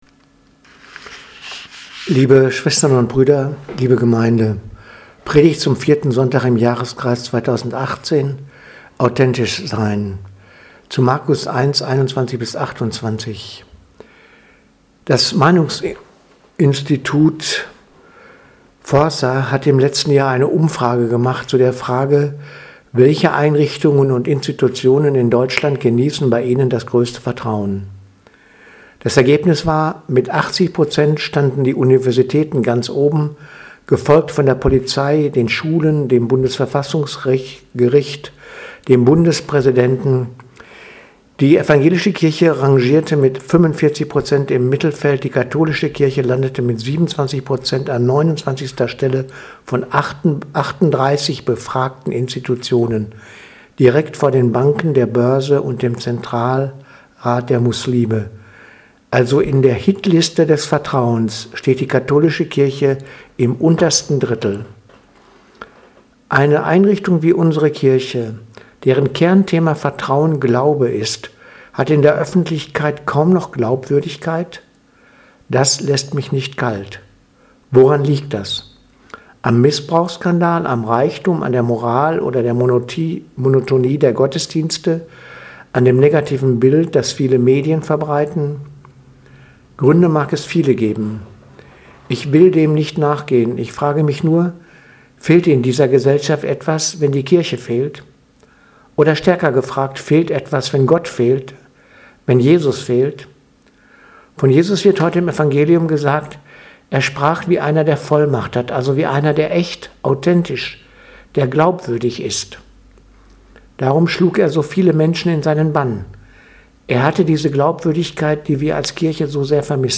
Predigt vom 27.01.2018 – Authentisch sein
2018-01-27- Predigt zum 4. Sonntag im Jahreskreis 2018 Authentisch sein